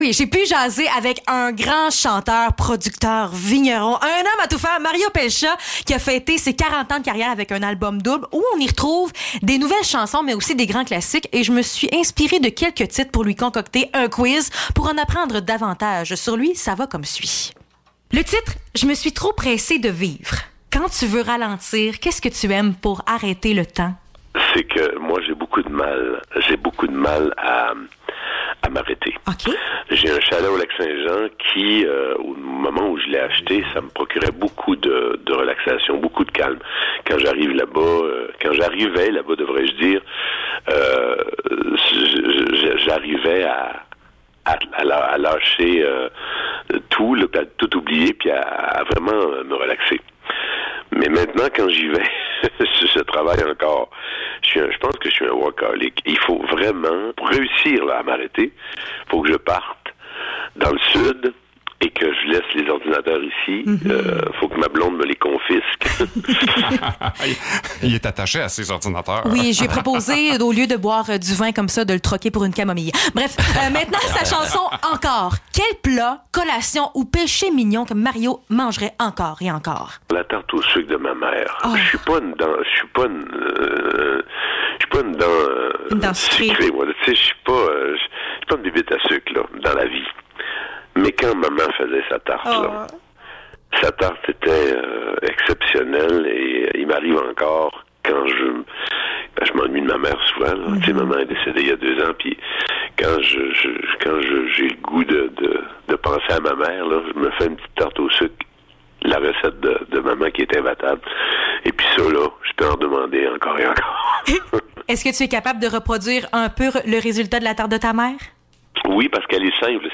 Entrevue avec Mario Pelchat (9 novembre 2021)